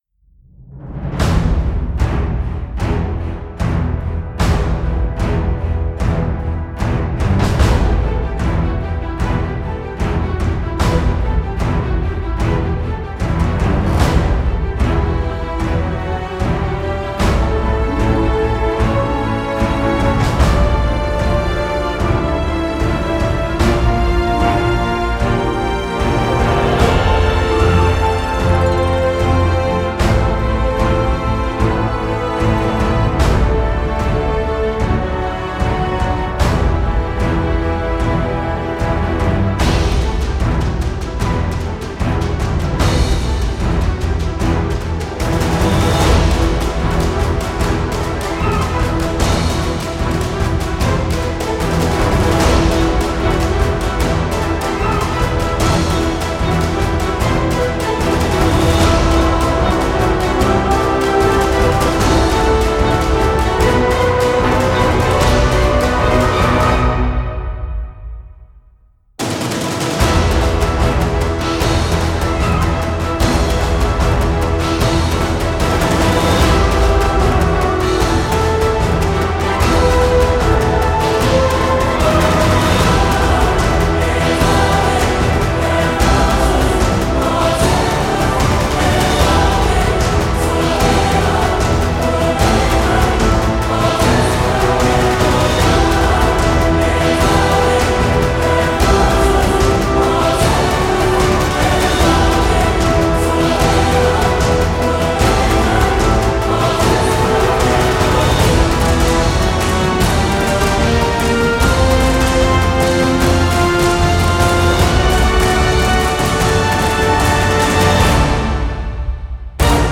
Epic Trailer Stuff
after a long time I want to post a new track that I finished today....Enjoy this huge epic track :-)
Very powerful and dynamic piece.
A battle scene coms to mind while listening.
It could be a little bassier, and around the 1:10 mark, it seems like it would be appropriate to have some electric guitar in there, but otherwise it's very well done.
The mix on my end was full and clean.
Kind of galactic in nature.